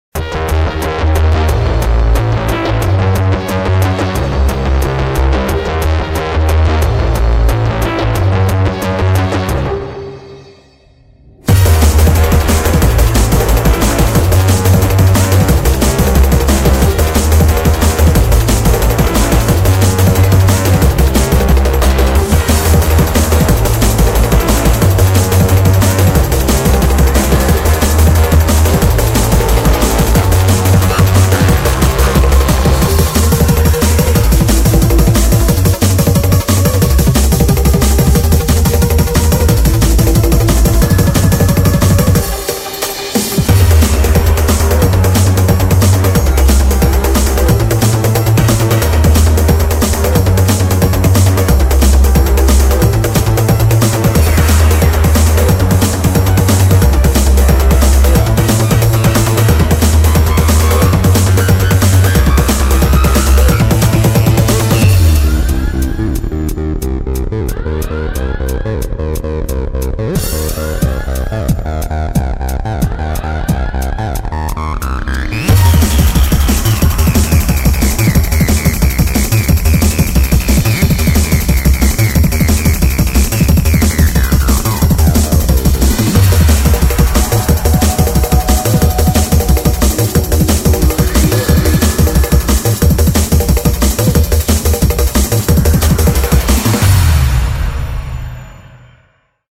BPM180-360
Audio QualityPerfect (High Quality)
in the very fast style of boss music.